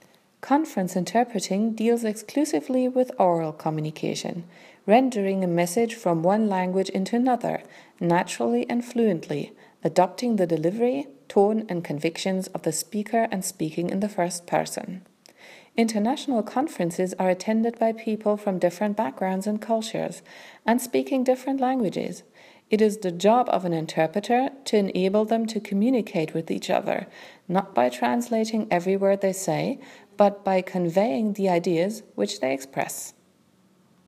MEINE STIMME